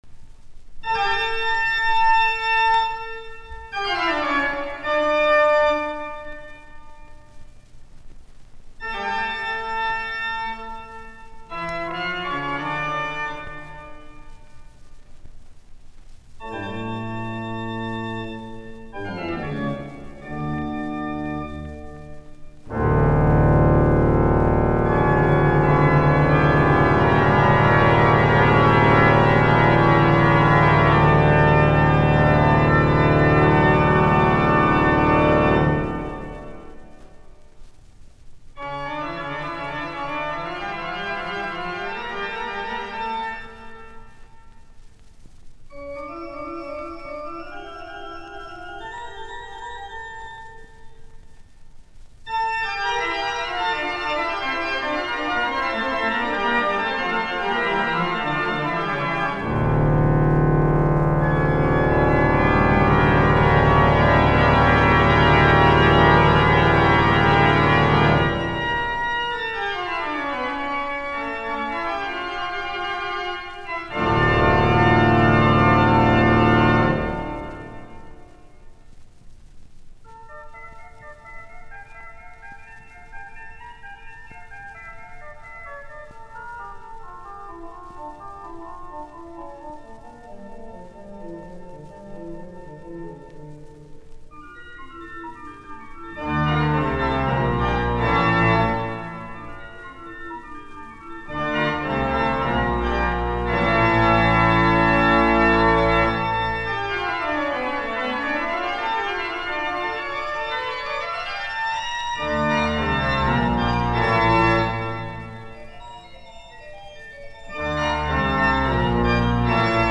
musiikkiäänite
Soitinnus: Urut